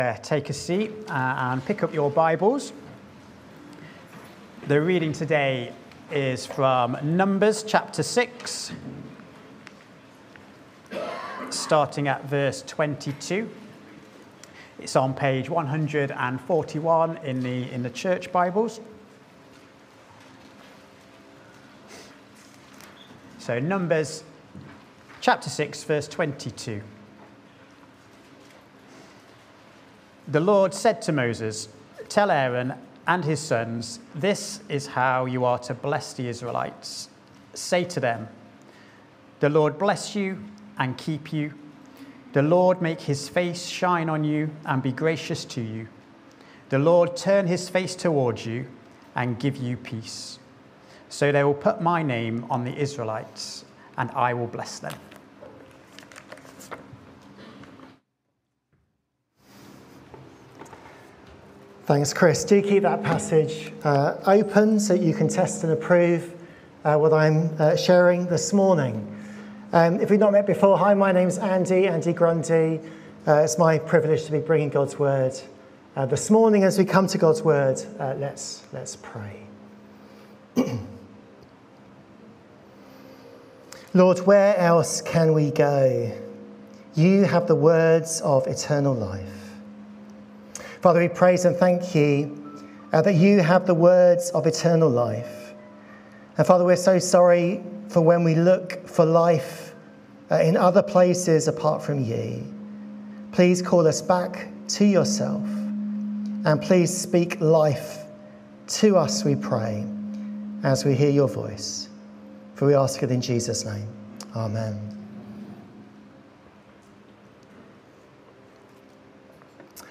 Standalone sermons | Beeston Free Church